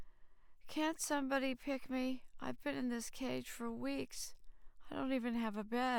emotional-speech
EARS-EMO-OpenACE / p103 /emo_sadness_freeform /reference.wav